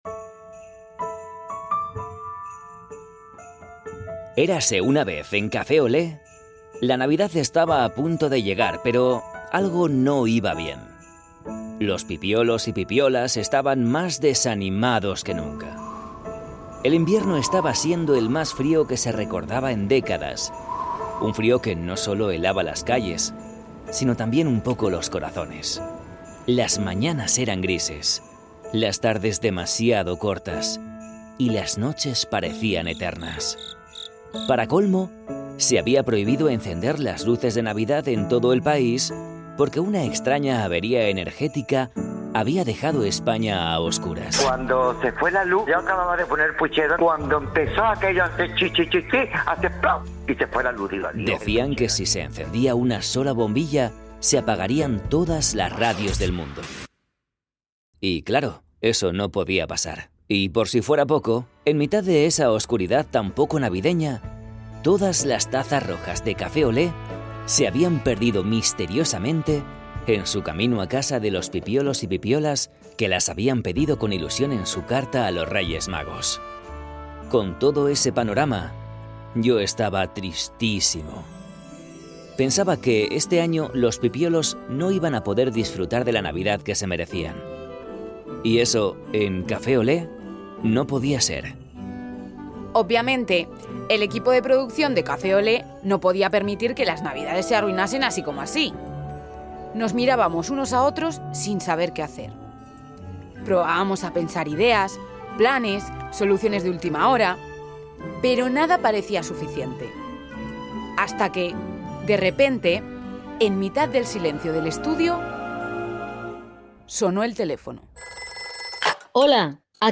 Nuestros colaboradores y colaboradoras nos han dado lo mejor de sí mismos para conseguir emocionaros en estas fechas tan especiales.